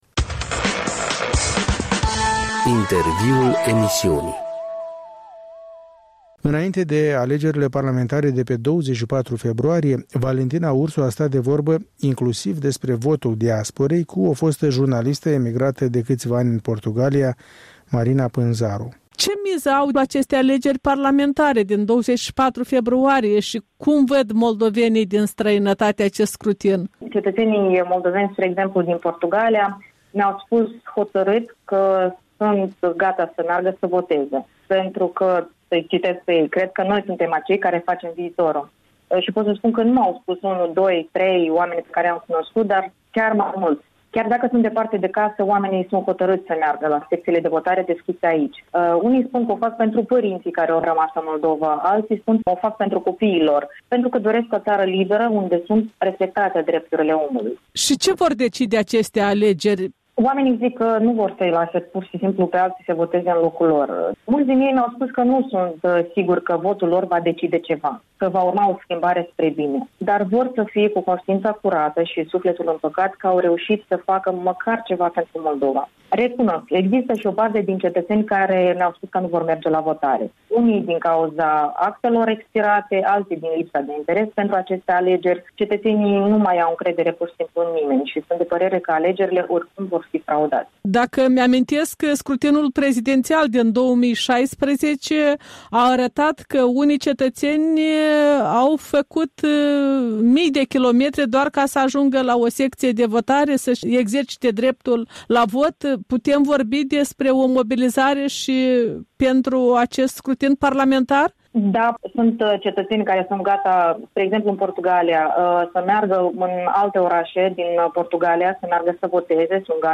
Un interviu cu o fostă jurnalistă emigrată de câțiva ani în Portugalia.